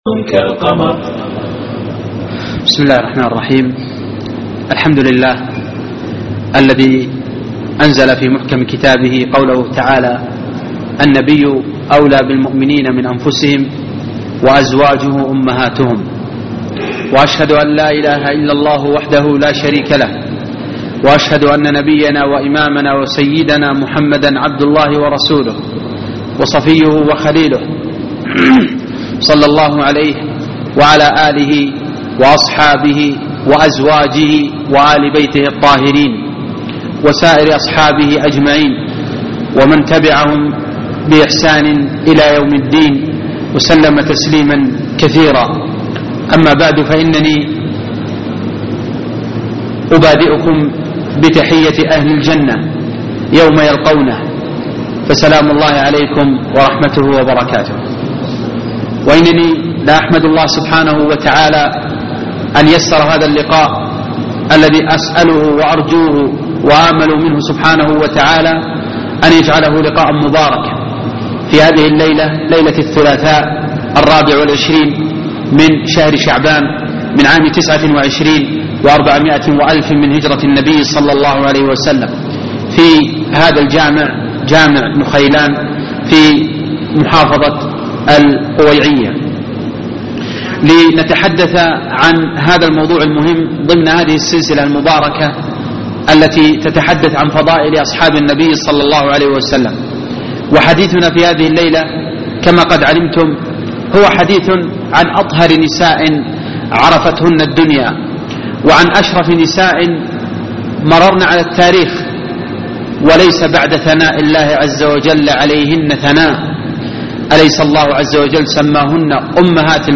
محاضرة اليوم